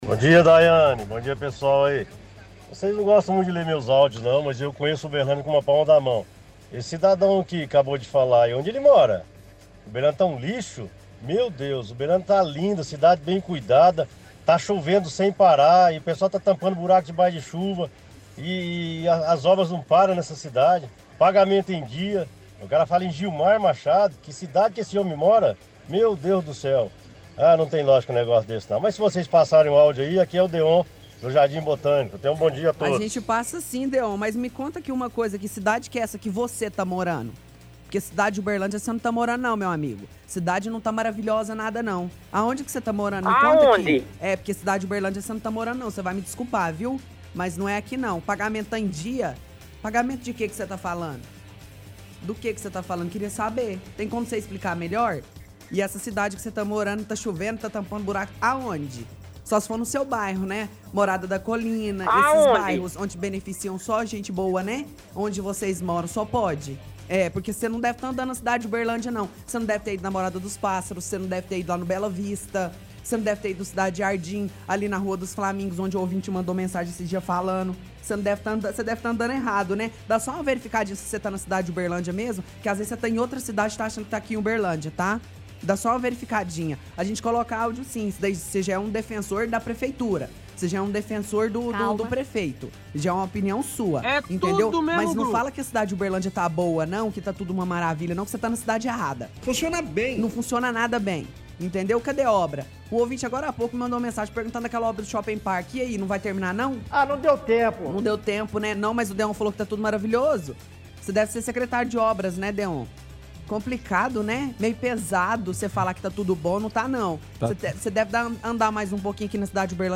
– Ouvinte envia áudio e reclama do áudio de outro ouvinte que disse que Uberlândia está um lixo.